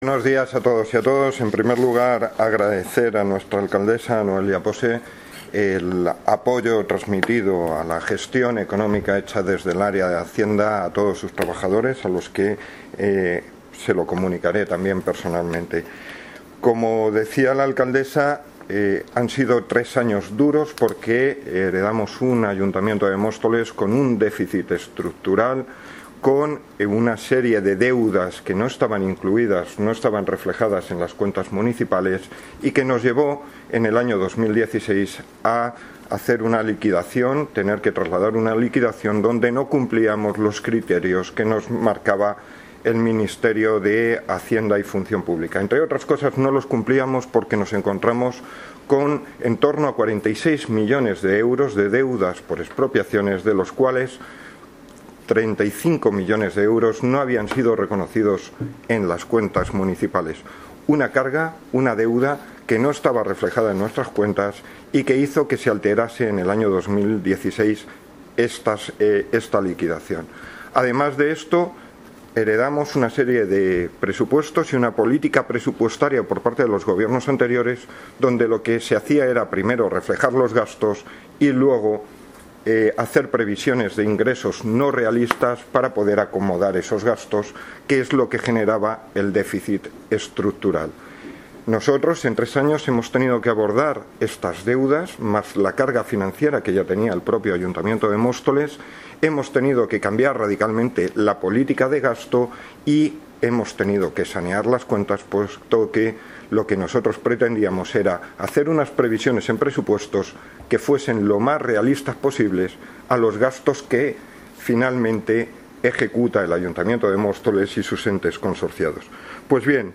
Audio - Francisco Javier Gómez (Concejal de Hacienda, Transporte y Movilidad) Sobre liquidación ejercicio económico 2017